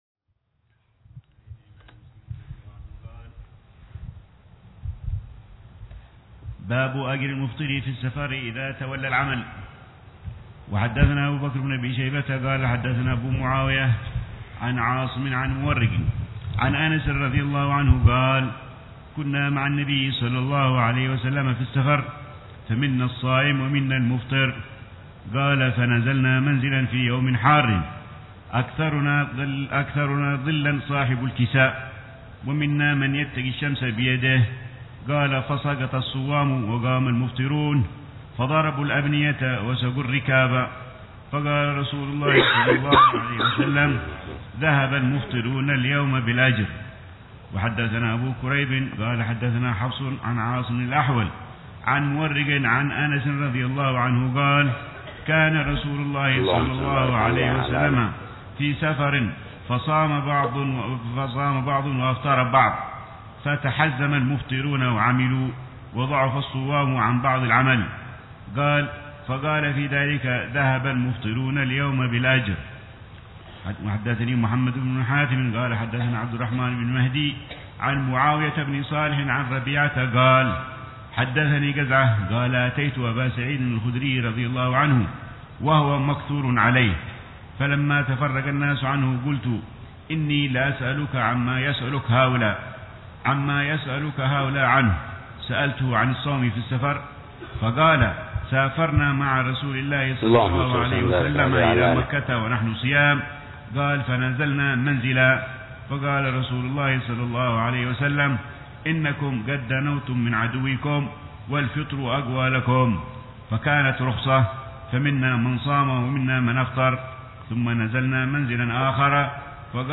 شرح الحبيب العلامة عمر بن حفيظ لكتاب الصوم من صحيح الإمام مسلم ومجموع الإمام النووي ضمن دروس روحات رمضان لعام 1439هـ